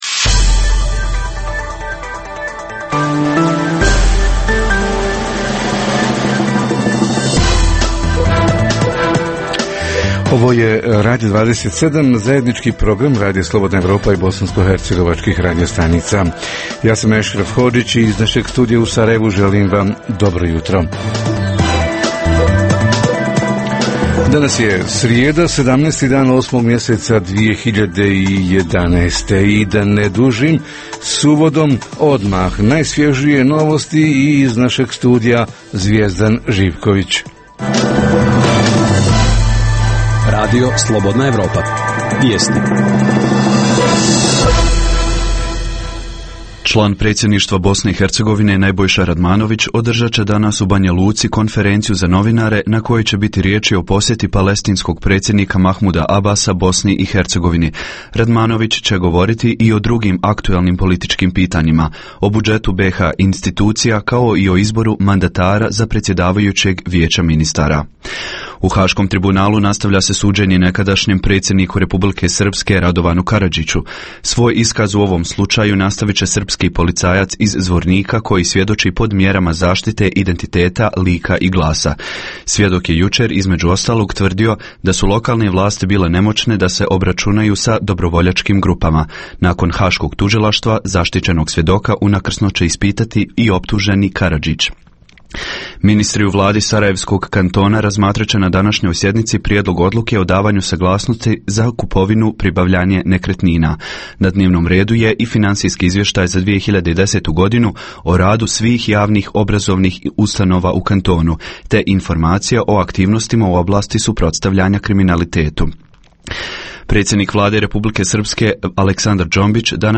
Uoči nove školske godine – je li sve spremno za početak? Reporteri iz cijele BiH javljaju o najaktuelnijim događajima u njihovim sredinama.